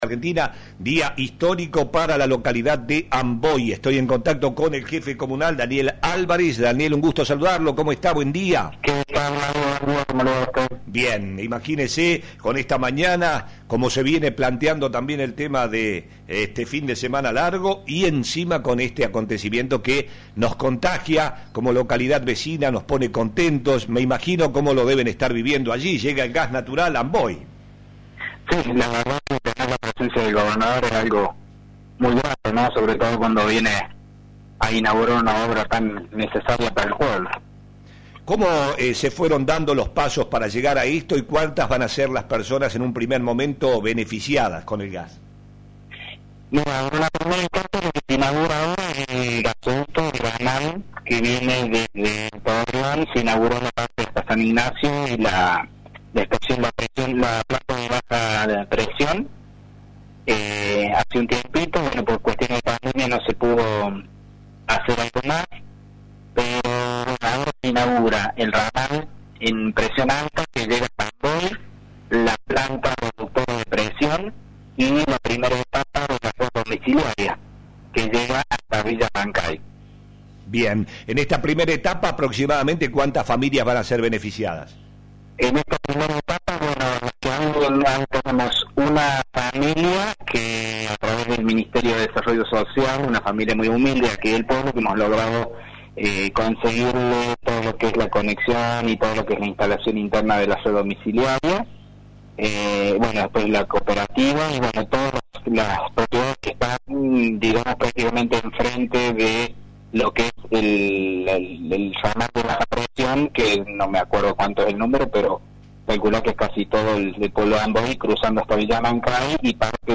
Dialogamos con el Jefe Comunal Daniel Alvarez quien se mostró feliz en éste día histórico que contará con la presencia del Gobernador. Alvarez además habló de las diferentes obras que se están llevando adelante en su pueblo con fondos aportados por los gobiernos provincial y nacional.